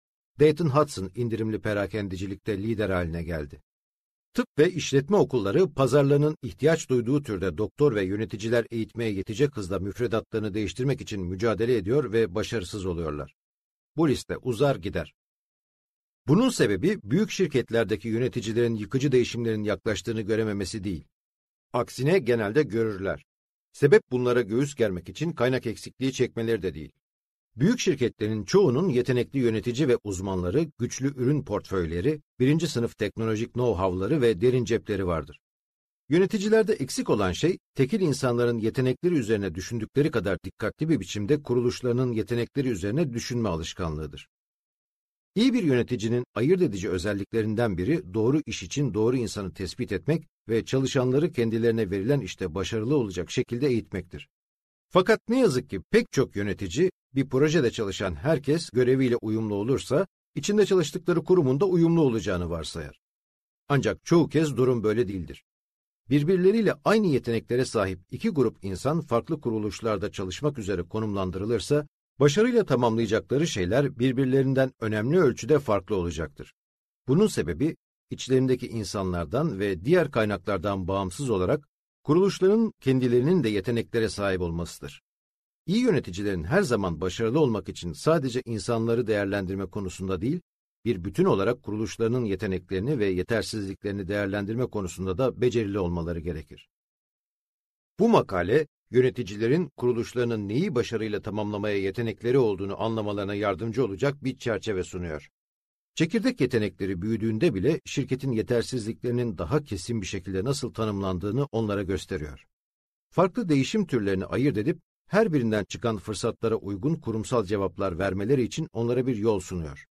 Esaslar - Seslenen Kitap